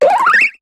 Cri de Marisson dans Pokémon HOME.